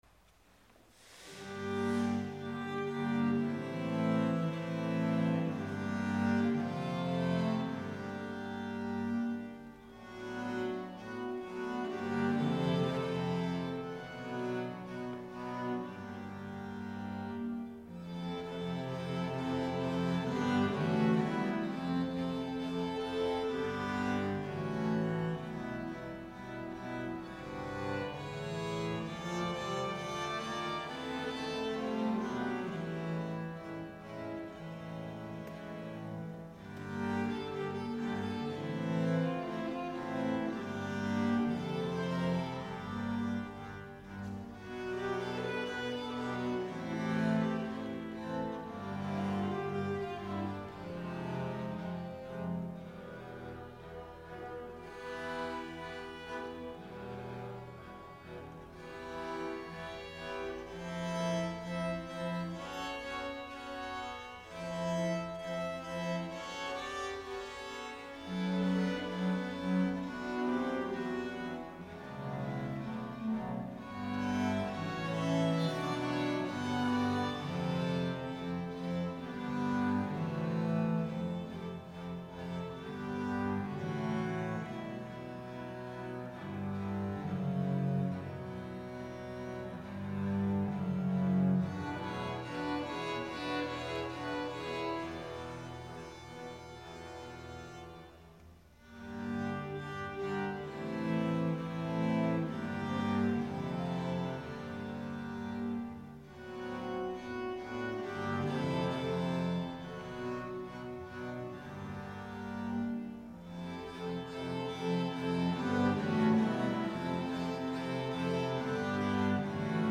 I found that Culpeper knew and played the music of John Dowland, so I decided on a Pavan and Galliard for viol consort.
The piece is performed by the Pastores Viol Consort at the Brighton Festival in May 2000.
pavane.mp3